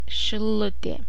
Silute.ogg.mp3